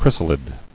[chrys·a·lid]